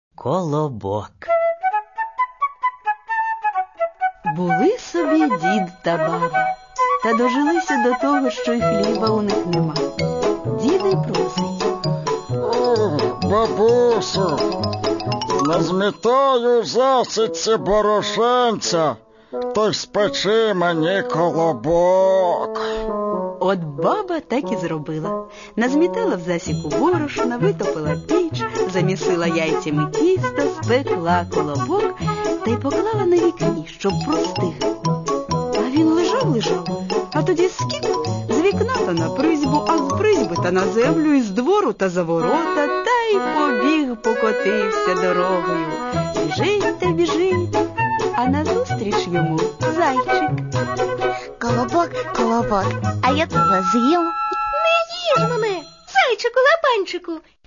Аудио книги
Он содержит семь сказок, и каждую из них по-своему интересно слушать, ибо и прочитаны хорошо – не всякие родители так прочитают, не поленятся – и музычка здесь играет, и есть общая канва, рассказ, который подводит к каждой сказке отдельно.